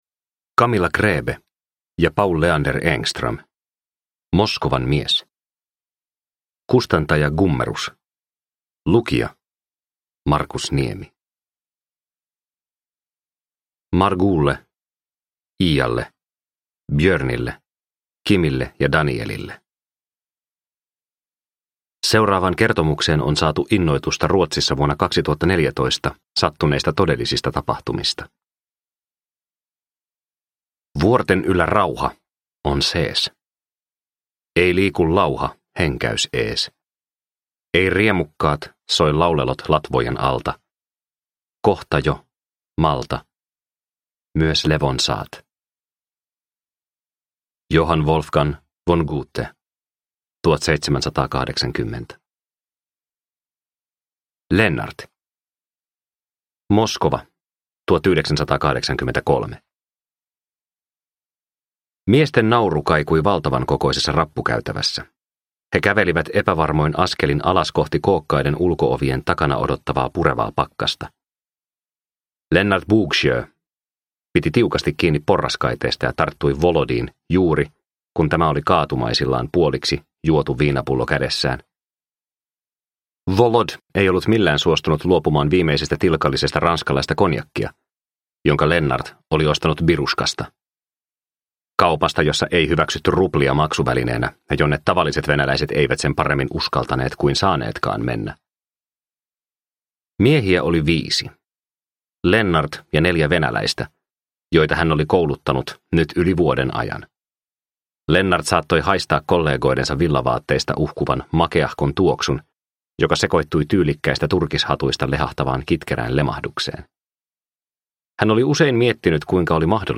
Moskovan mies – Ljudbok – Laddas ner